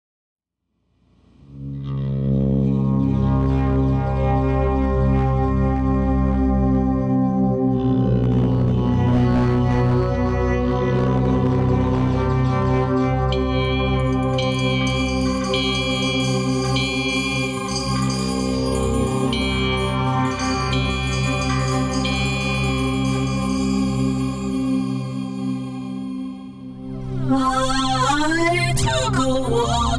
Adventurous Electronic Excursions
Voice with Live Processing
Guitar and SuperCollider